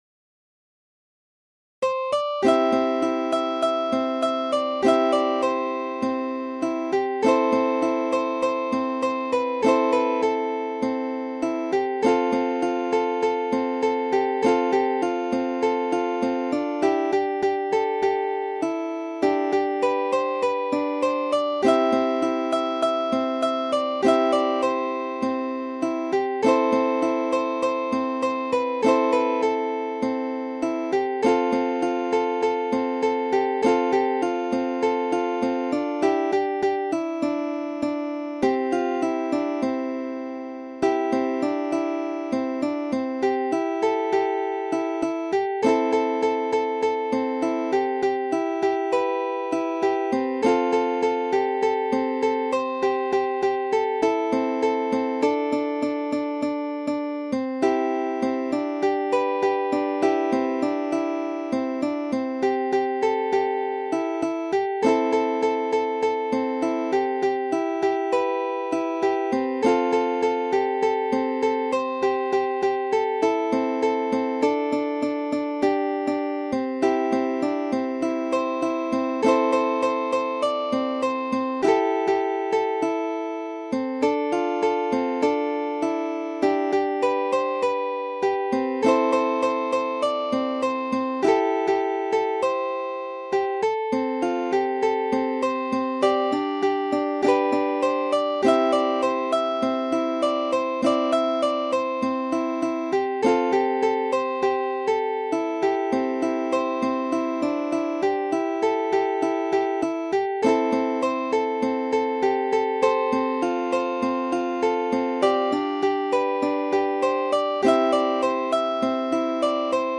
Ukulele Fingerstyle Solo Tab 乌克丽丽 指弹 独奏 谱